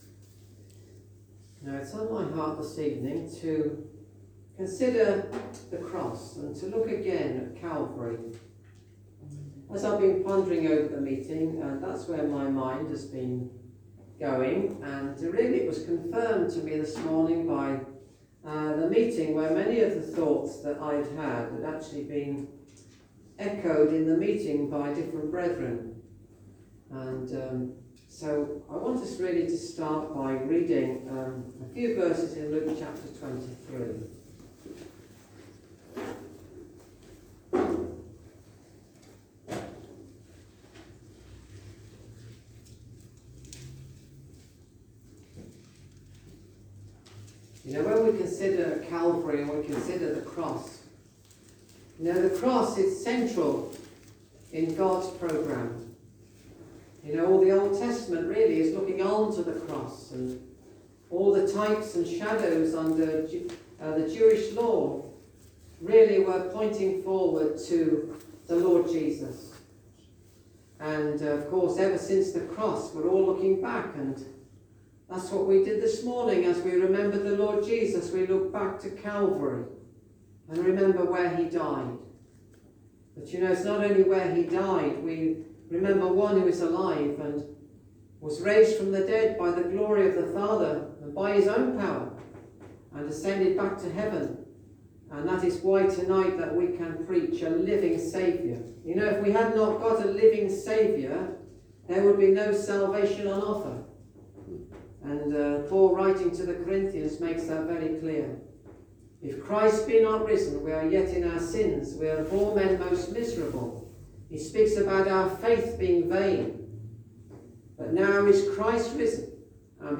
An audio recording taken from the weekly preaching of the Lord Jesus Christ as the Saviour of Sinners. In this recording, the speaker centres his thoughts upon the Cross.
Service Type: Gospel